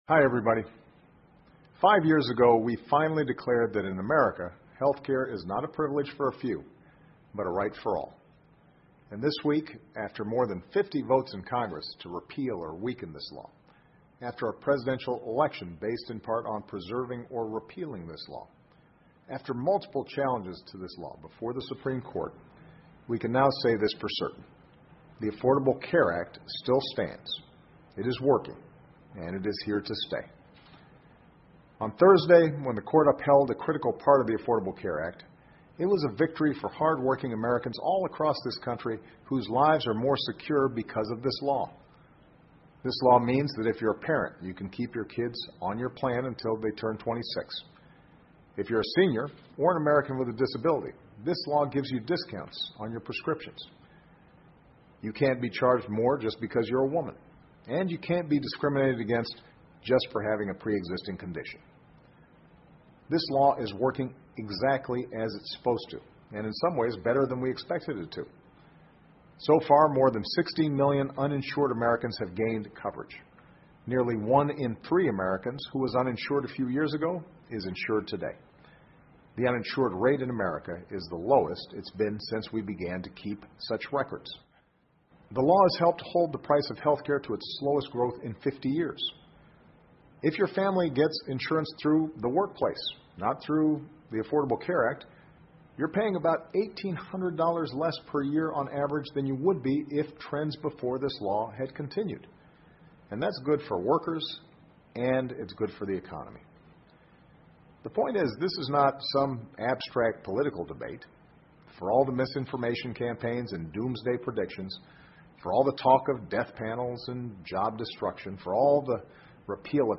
奥巴马每周电视讲话：总统期望平价医保法继续为民服务 听力文件下载—在线英语听力室